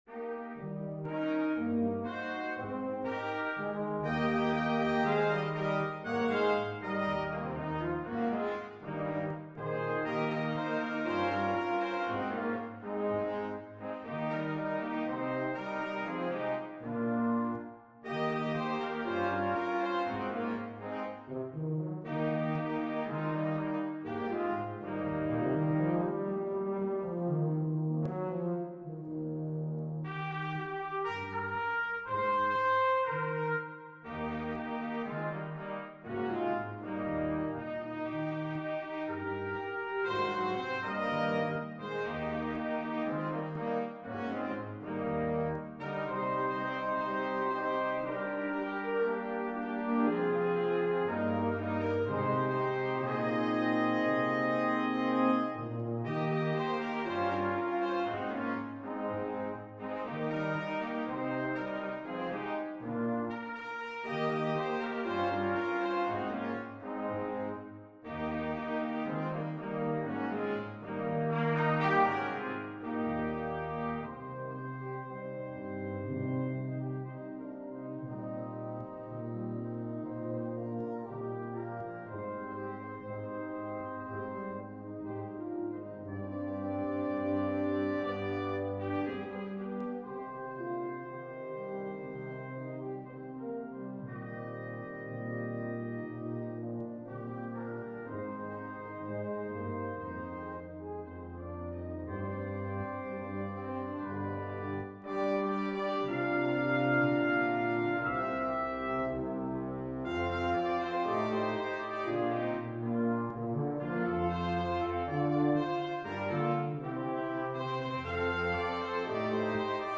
2 Trumpets,Horn,3 Trombones,Tuba